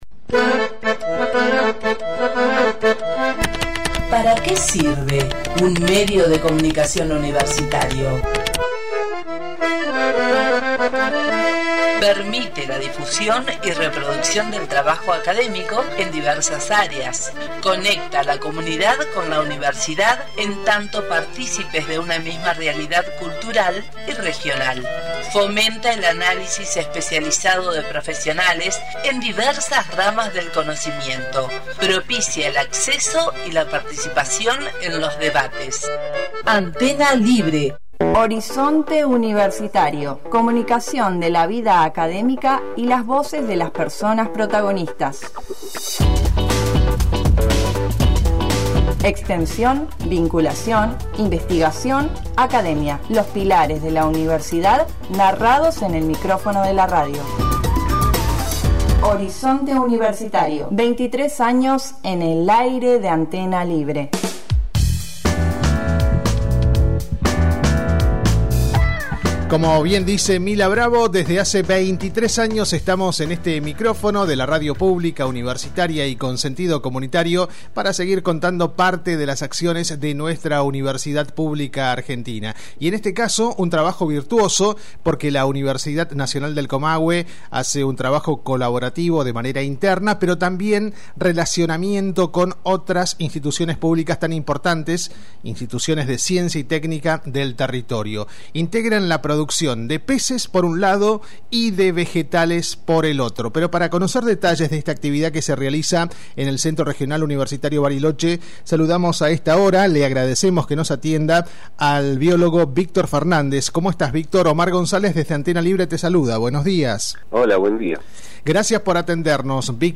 Horizonte Universitario , hablamos con el biólogo